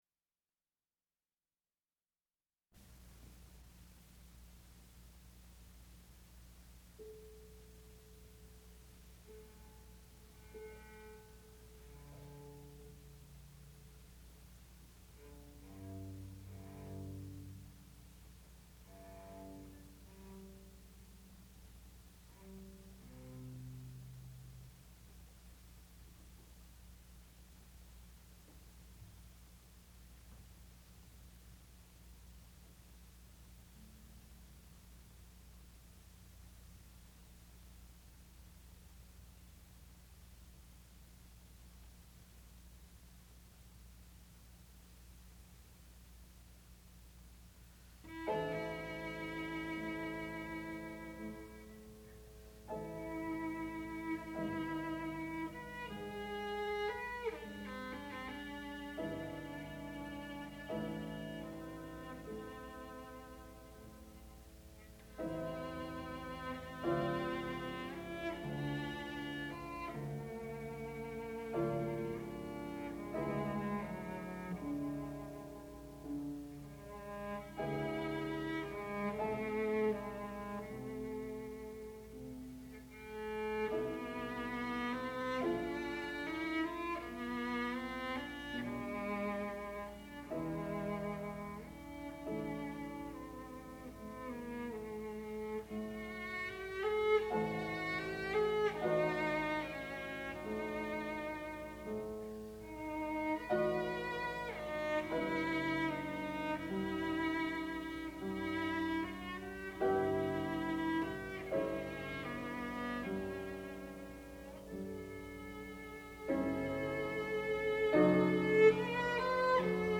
sound recording-musical
classical music
cello
Graduate Recital